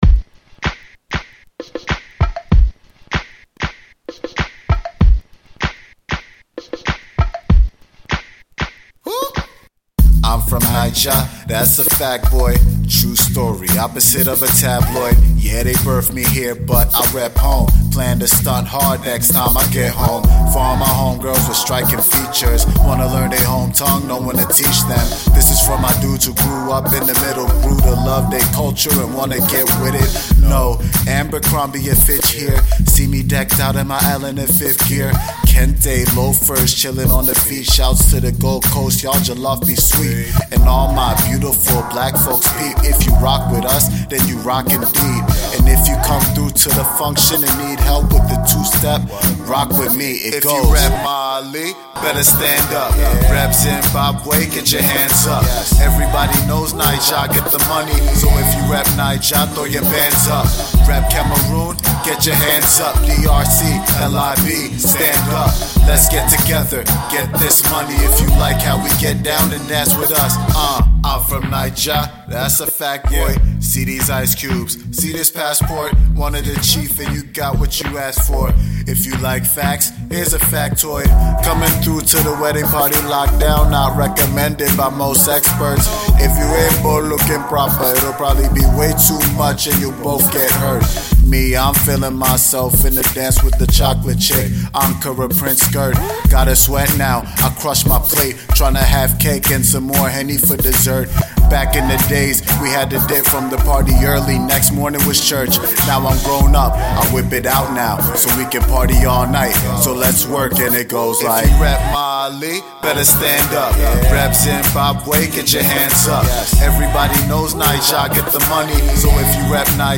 AudioHip-HopRap
Nigerian-American rapper
Gifted with a smooth flow and deep, resonant voice